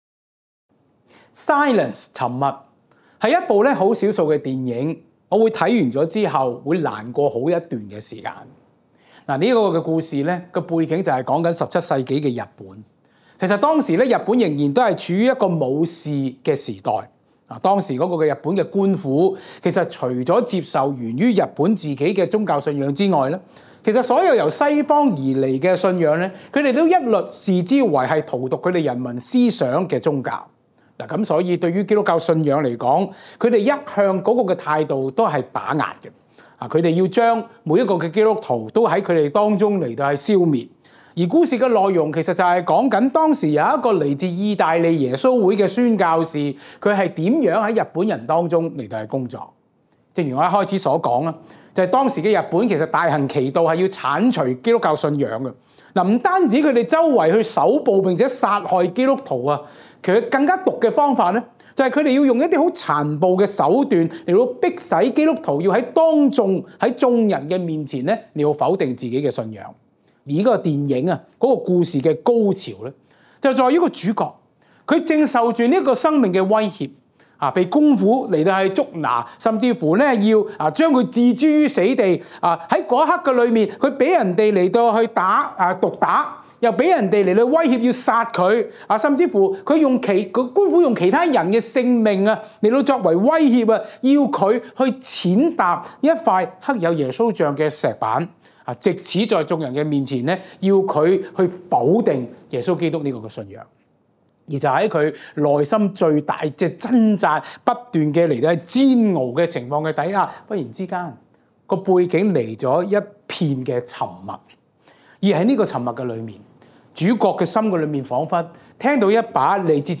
講道